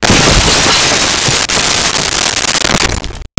Explosion Short Glass Debris
Explosion Short Glass Debris.wav